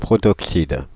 Ääntäminen
Ääntäminen Paris: IPA: [pʁɔ.tɔk.sid] France (Île-de-France): IPA: /pʁɔ.tɔk.sid/ Haettu sana löytyi näillä lähdekielillä: ranska Käännös Konteksti Substantiivit 1. monoxide kemia 2. protoxide kemia Suku: m .